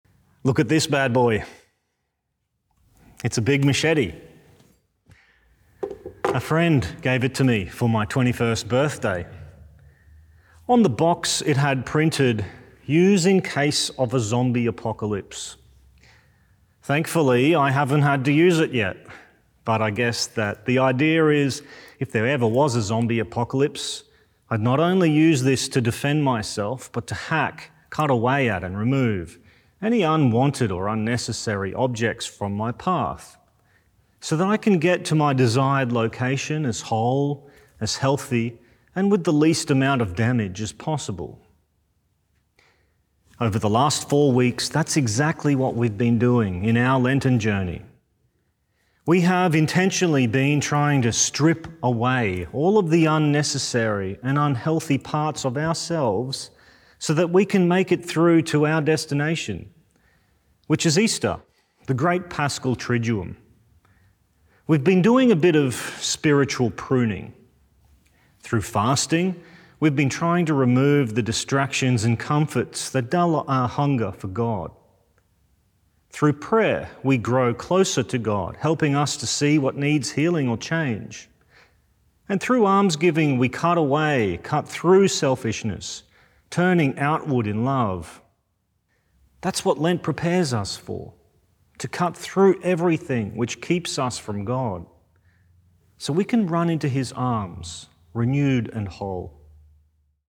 Fourth Sunday of Lent - Two-Minute Homily